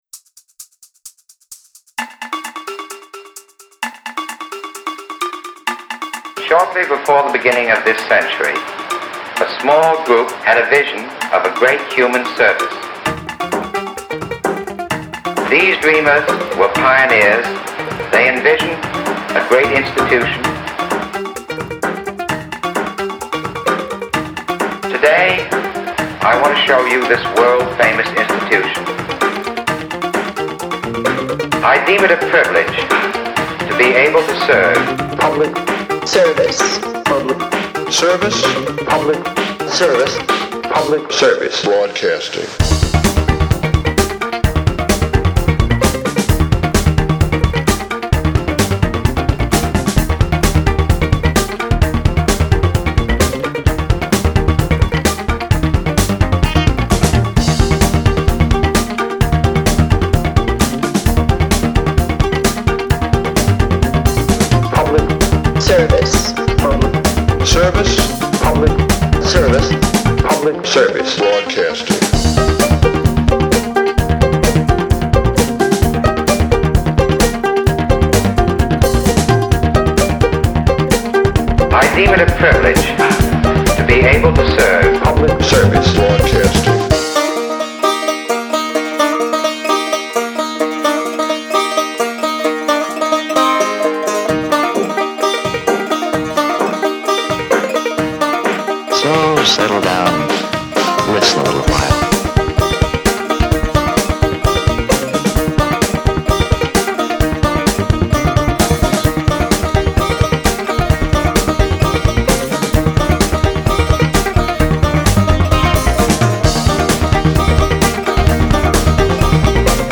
guitar
bass
uplifting